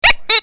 resources/phase_4/audio/sfx/clock07.mp3 at 29f25cb59b97a03f634e50d9e57d7703d6855d29
clock07.mp3